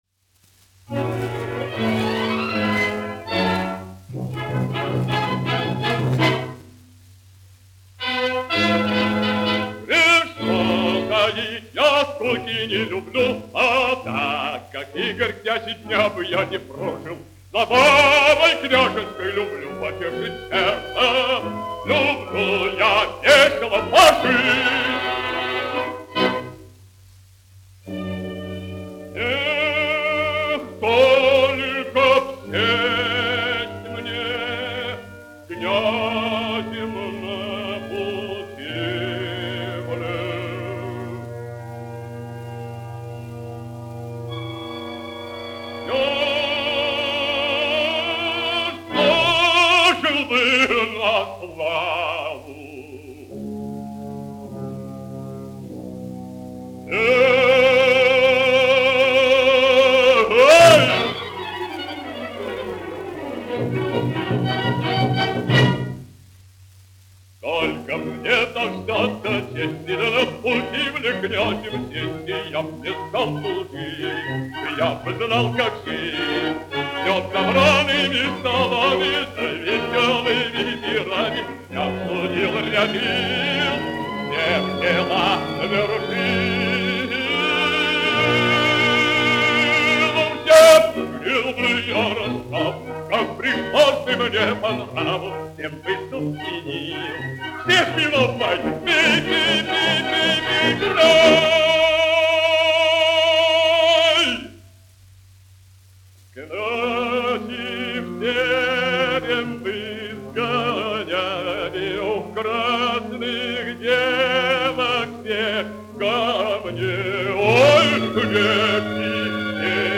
Шаляпин, Федор Иванович, 1873-1938, dziedātājs
1 skpl. : analogs, 78 apgr/min, mono ; 25 cm
Operas--Fragmenti
Latvijas vēsturiskie šellaka skaņuplašu ieraksti (Kolekcija)